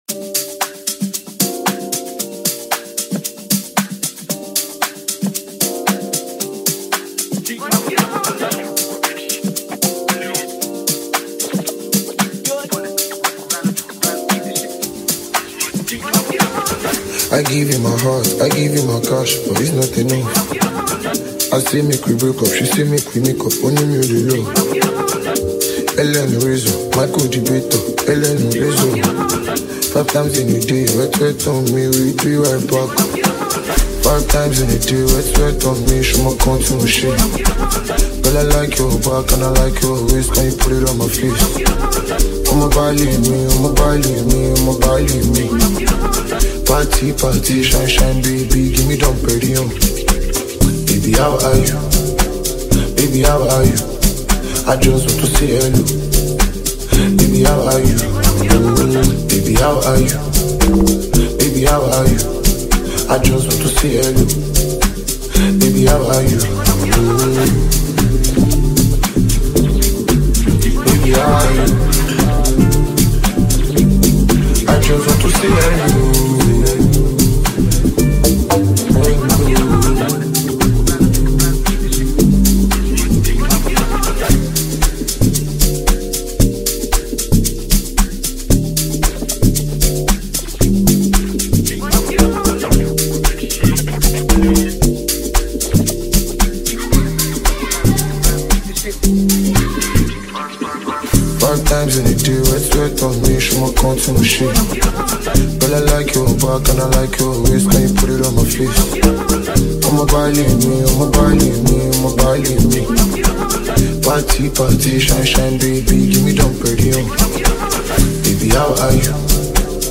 features a soulful and introspective vibe